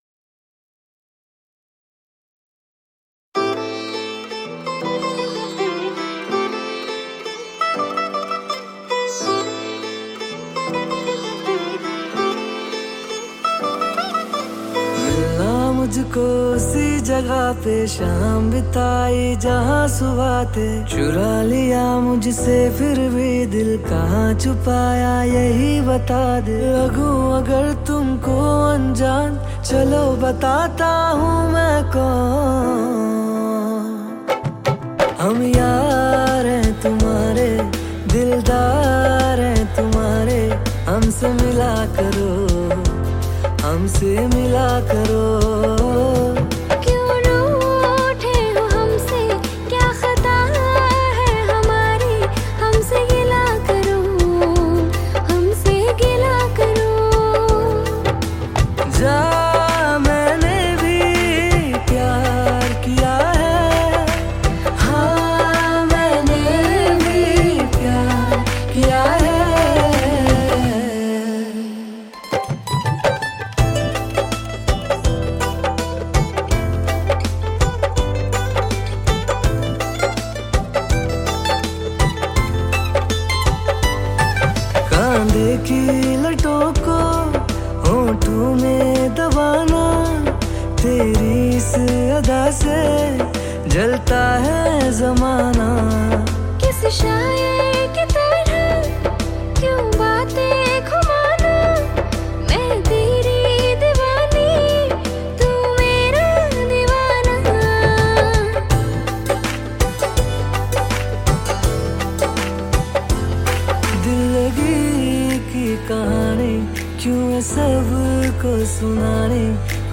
Category New Cover Mp3 Songs 2021 Singer(s